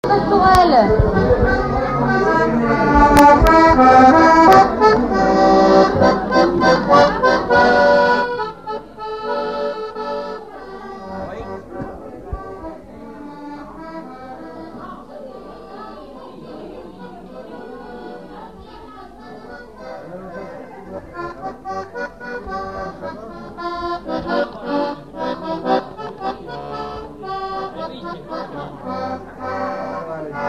Figure de quadrille
instrumental
danse : quadrille : pastourelle
Veillée de collectage de chants et de danses
Pièce musicale inédite